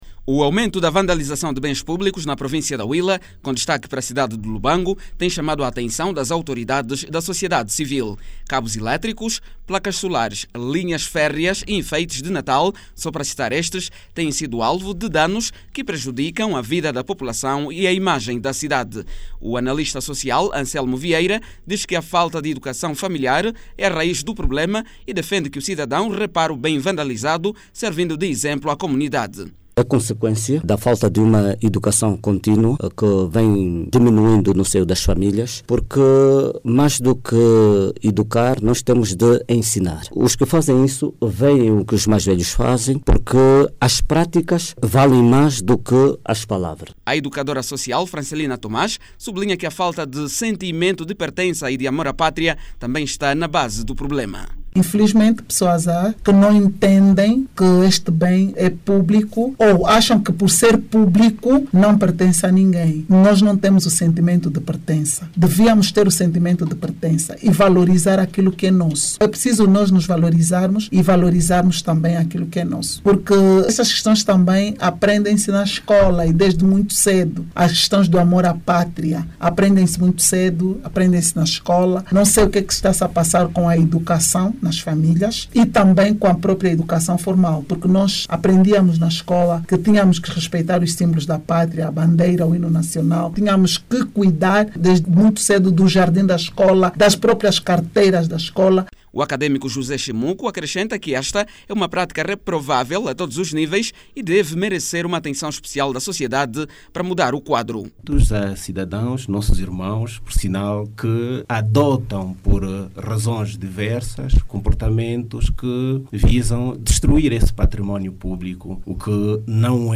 Algumas personalidades defendem a realização de actividades de sensibilização junto das comunidades, com vista a combater este mal e preservar os bens públicos. Jornalista